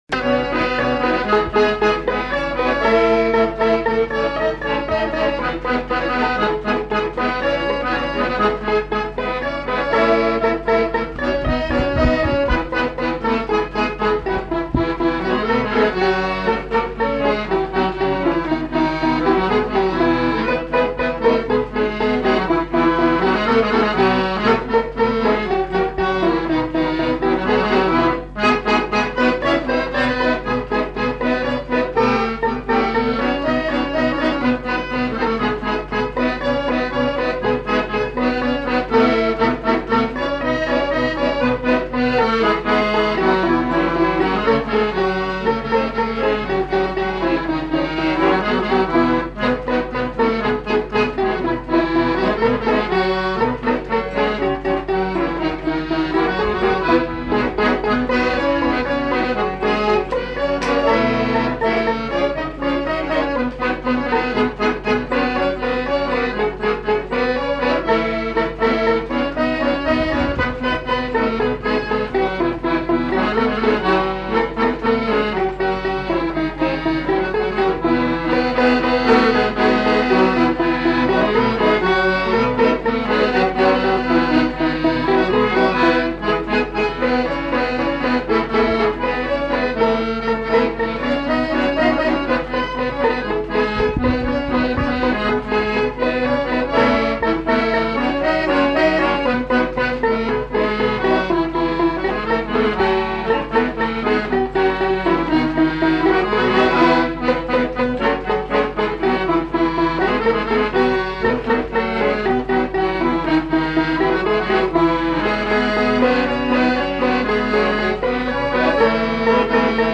Aire culturelle : Petites-Landes
Genre : morceau instrumental
Instrument de musique : violon ; accordéon chromatique
Danse : polka
Ecouter-voir : archives sonores en ligne